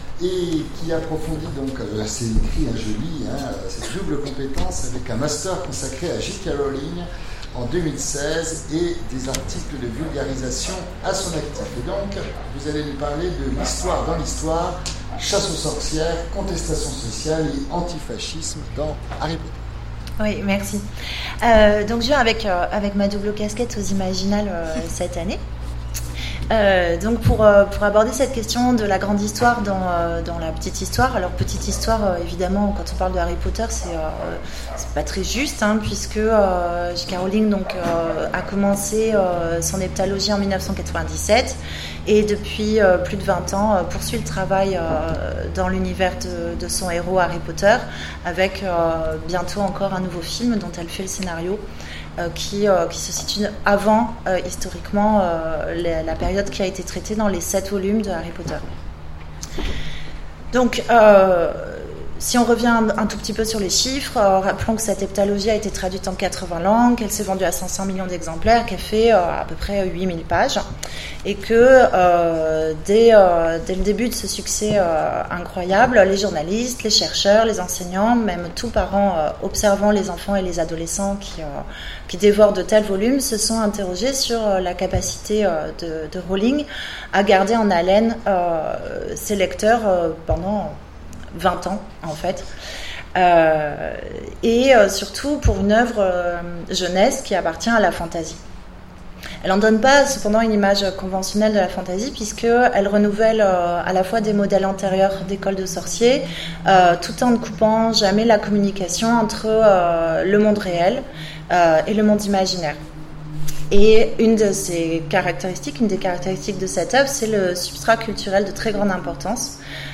Mots-clés Harry Potter Conférence Partager cet article